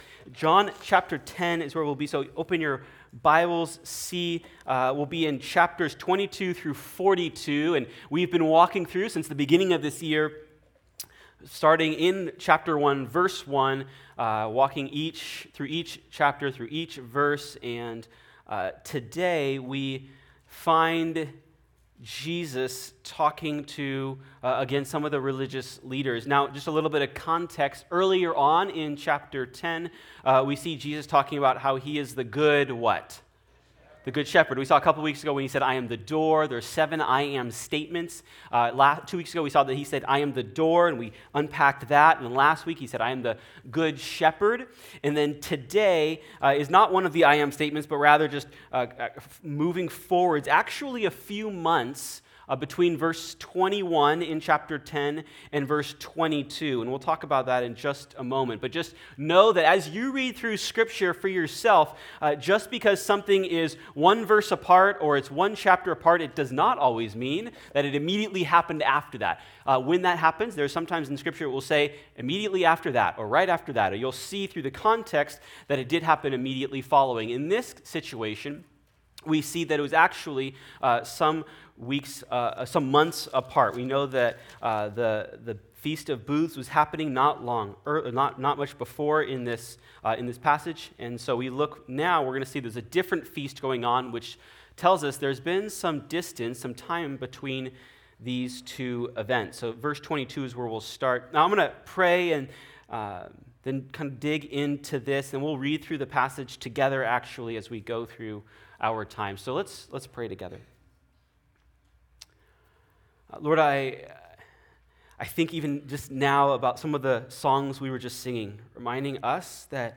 Sermon Notes:Coming soon.